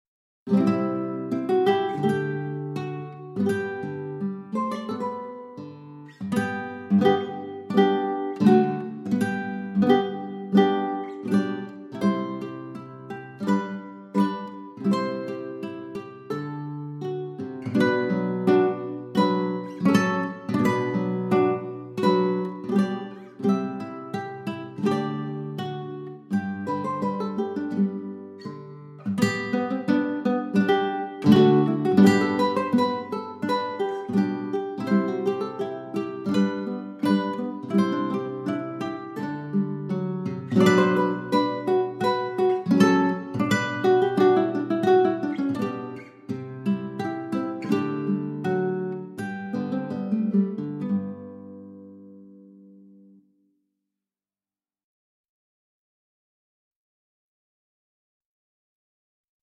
VS Tudor Pageant (backing track)